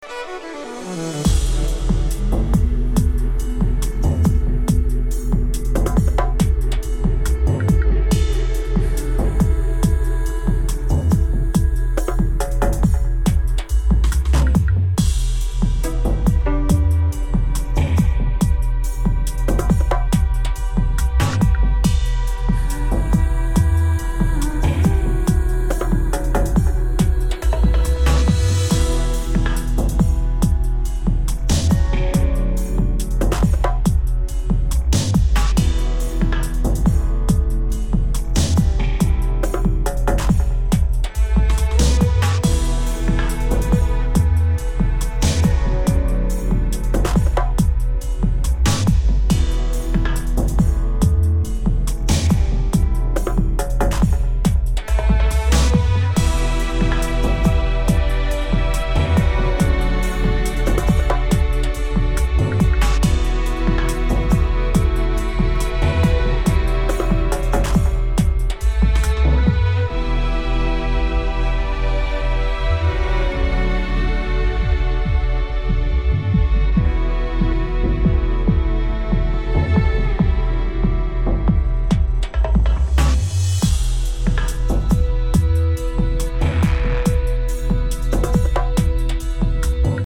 supplier of essential dance music
devoted to promoting the deeper sounds of dubstep